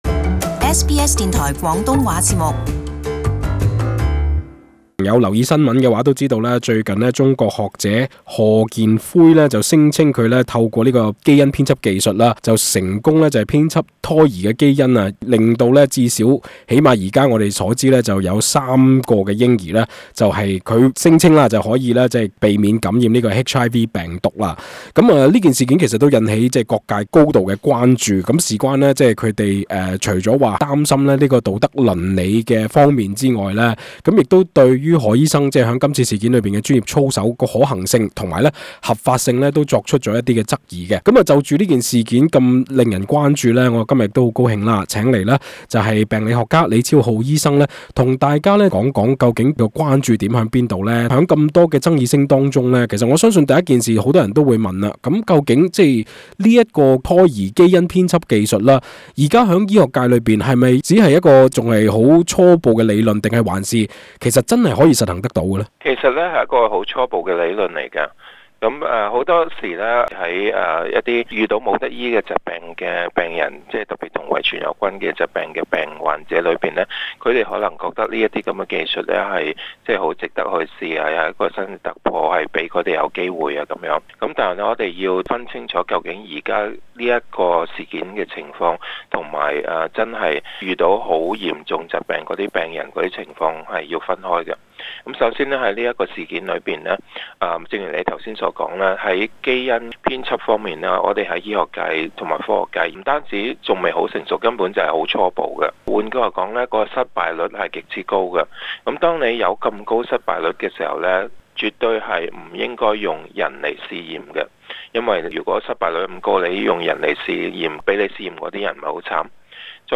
【社區專訪】基因改造目前有何規範與準則？